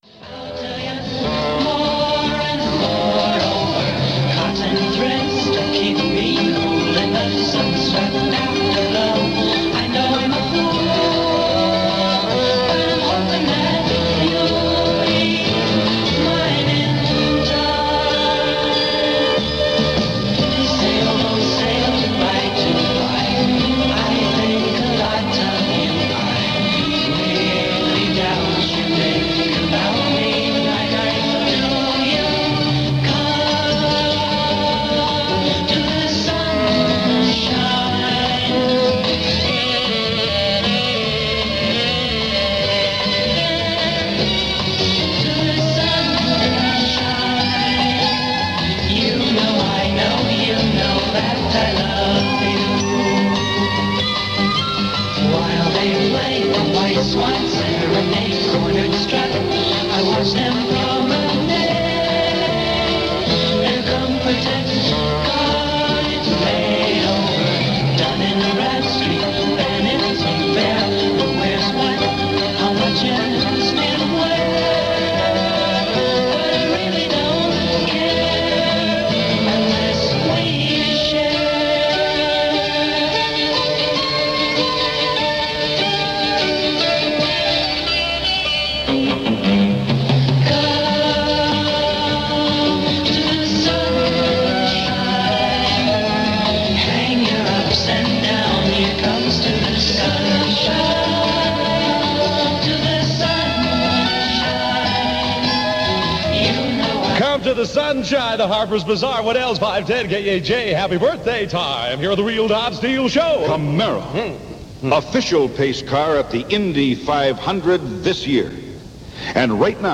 But you had The Real Don Steele every day from Boss Radio and that made it all right with the world – as it did on May 3, 1967.